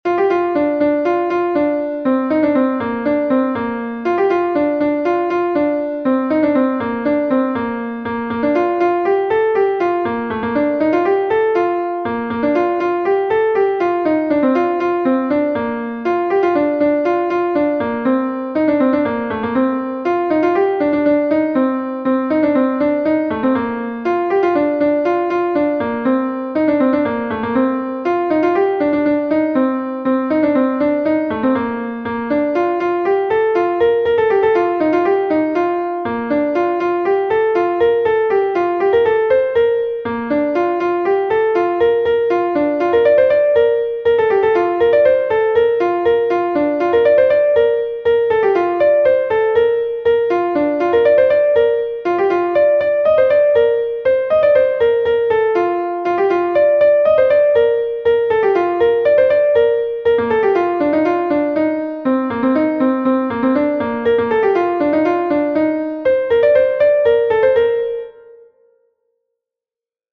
Gavotenn Prijag is a Gavotte from Brittany